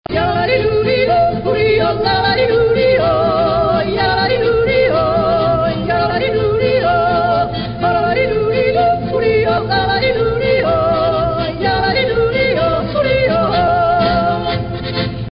yodel